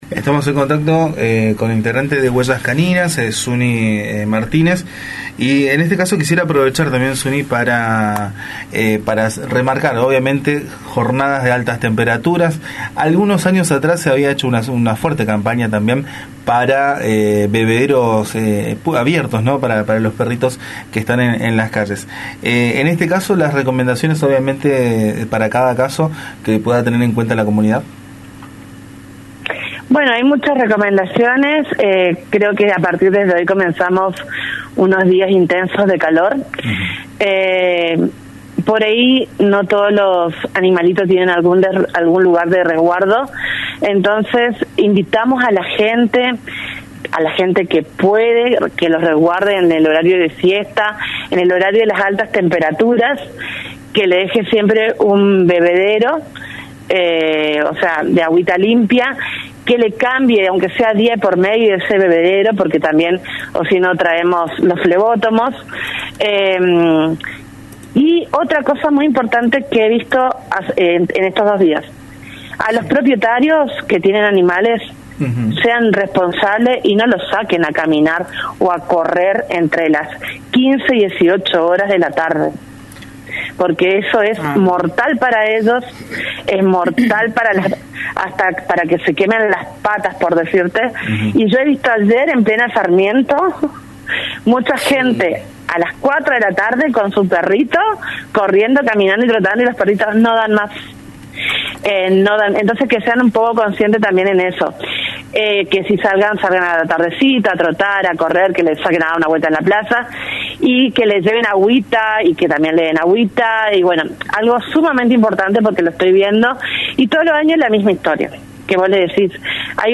charló con Radio Facundo Quiroga sobre la necesidad de concientizar sobre el cuidado de los animales abandonados durante las jornadas de altas temperaturas.